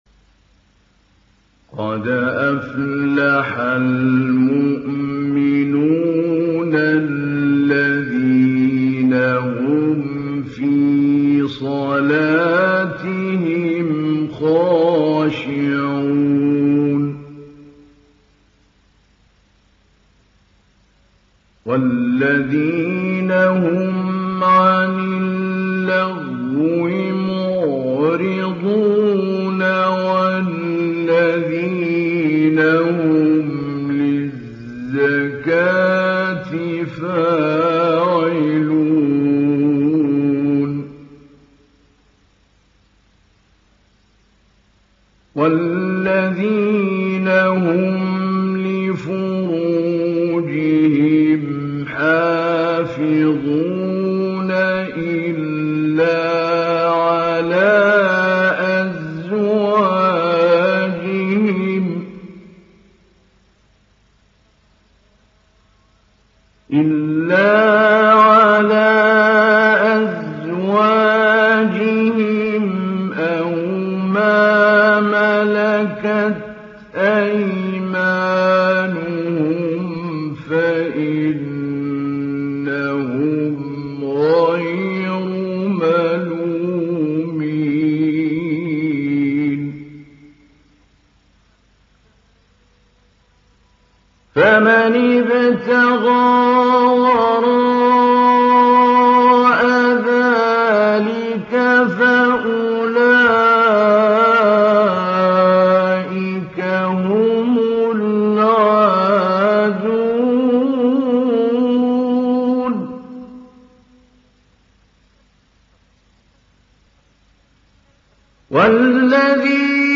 ডাউনলোড সূরা আল-মু’মিনূন Mahmoud Ali Albanna Mujawwad